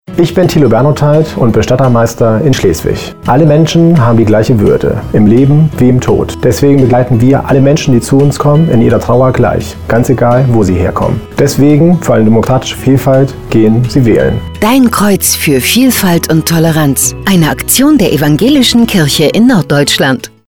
Um möglichst viele Menschen zu erreichen, haben wir Radiospots in norddeutschen Sendern geschaltet, die wir in Kooperation mit dem Evangelischen Presseverband Nord produziert haben. Sechs Menschen aus unserer Landeskirche haben mitgemacht und ein „Testimonial“ eingesprochen.